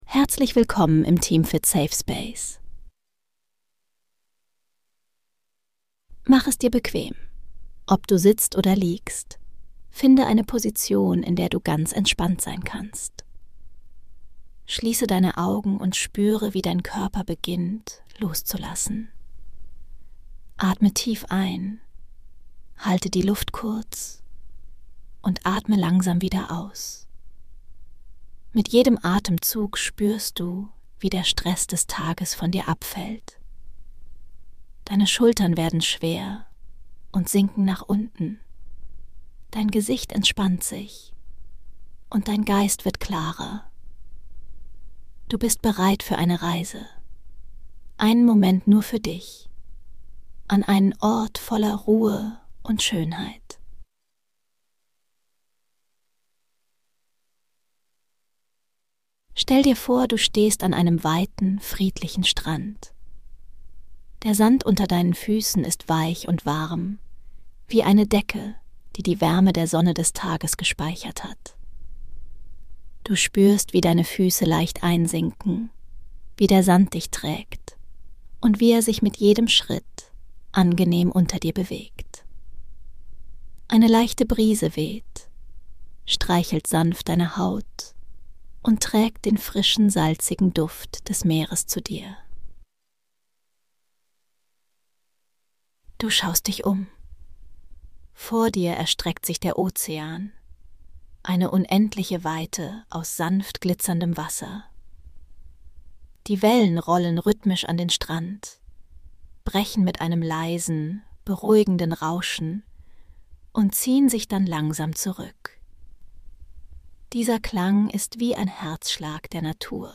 Eine meditative Reise zu einem Sonnenuntergang am Strand.